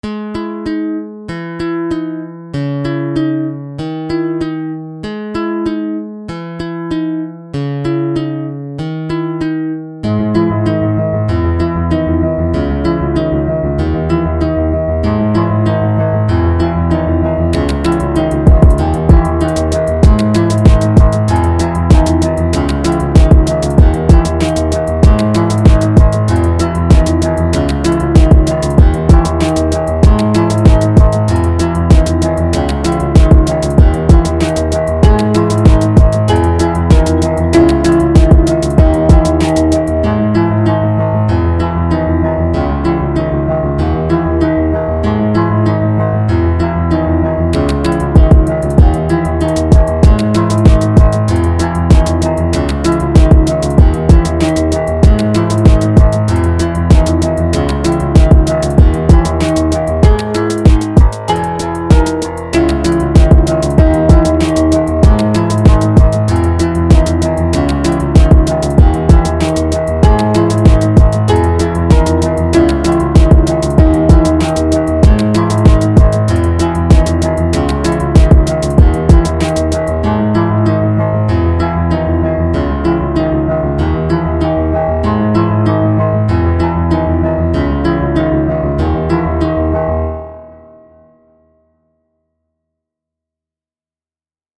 Rap Грустный 96 BPM